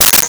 Sword Hit 03
Sword Hit 03.wav